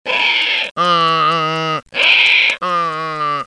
Donkey 1 Bouton sonore